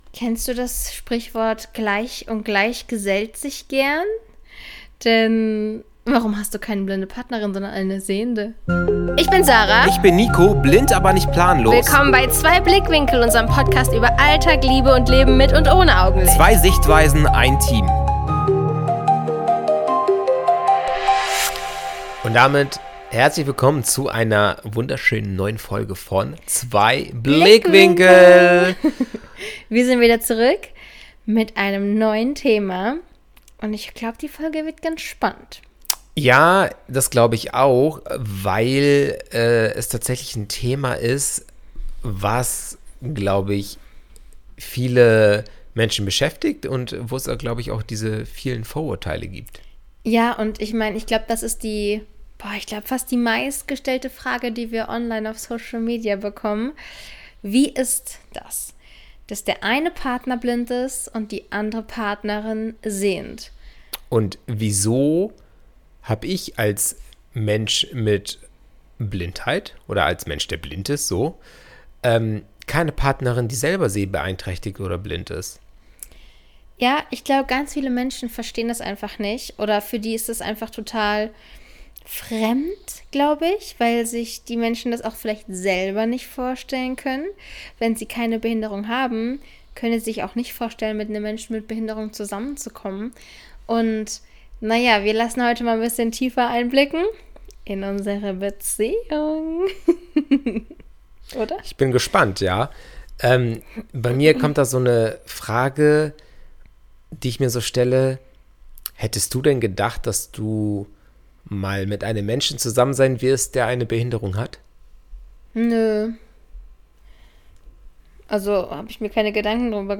Was passiert, wenn ein blinder Mann und seine sehende Frau über Alltag, Liebe und Barrieren quatschen? Jede Menge ehrliche Einblicke, Lachanfälle und die Erkenntnis: Man kann dieselbe Welt mit ganz unterschiedlichen Augen sehen, oder eben auch mal gar nicht.